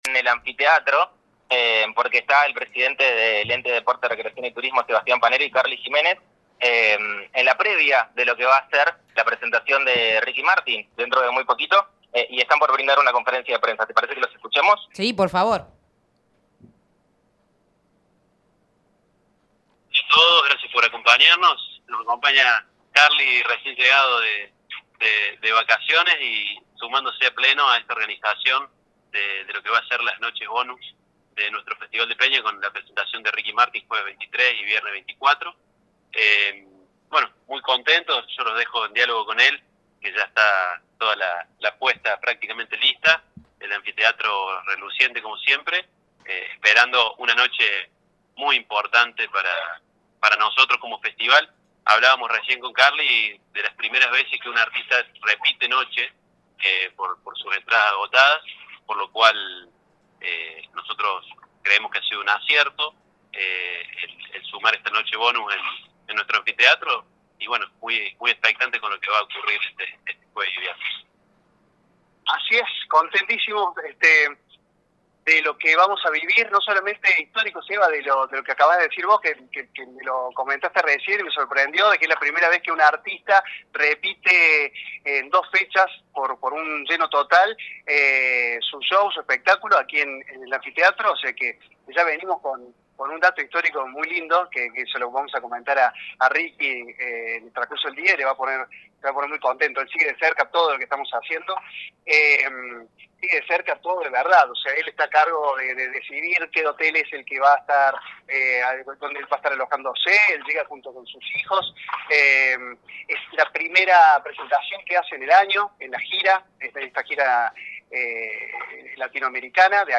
recorrieron el Anfiteatro para revisar los detalles técnicos y ofrecieron una conferencia de prensa.